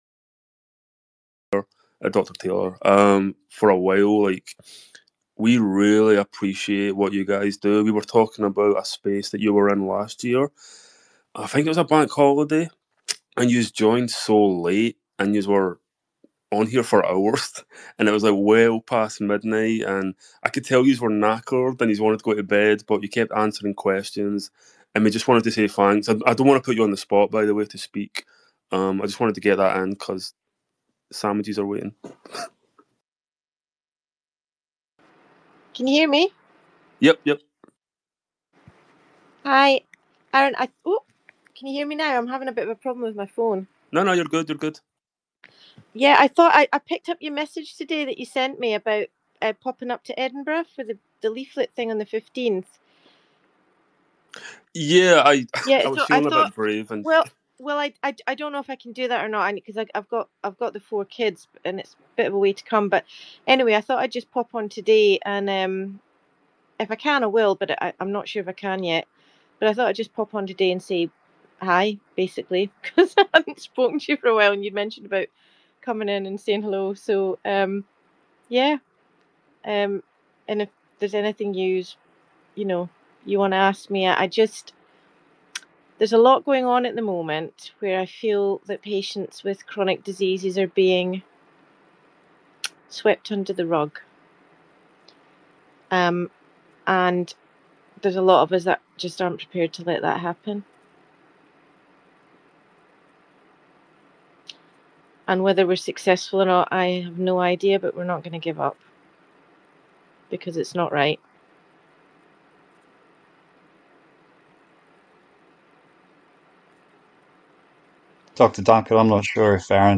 Alas Twitter/X deletes space recordings after 30 days and this is an important interview for Long Covid and ME/CFS sufferers.